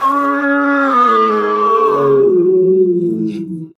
Queen grumble's death sound